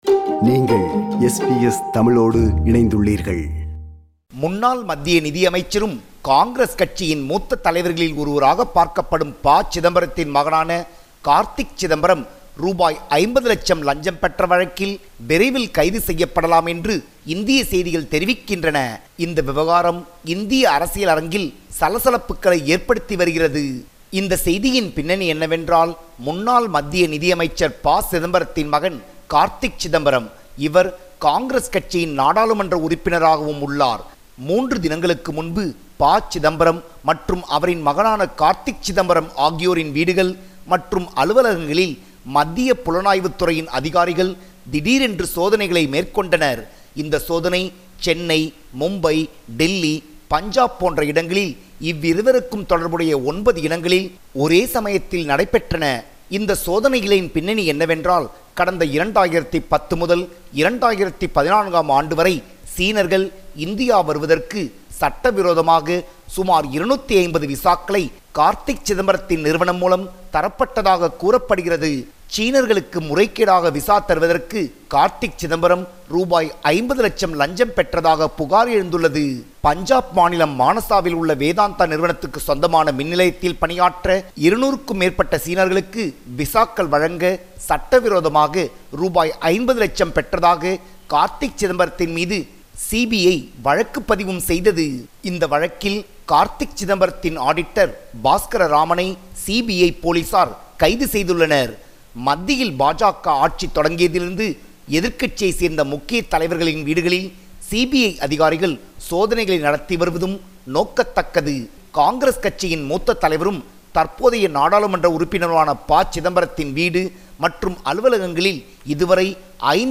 our correspondent in Tamil Nadu, compiled this report.